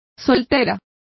Complete with pronunciation of the translation of spinster.